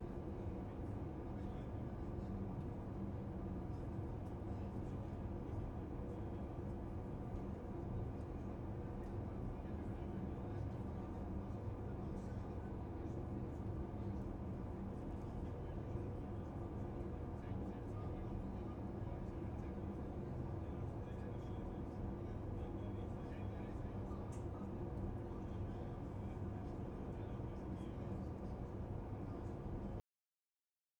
Additionally, it puts the adaptive nature of some ANC systems to the test, as the noise in these tests isn't constant and contains transient sounds like phones ringing and large vehicles accelerating.
You may need to raise your device's volume to distinguish additional details since our output recordings aren't very loud.
Airplane Noise
airplane-noise-sample.wav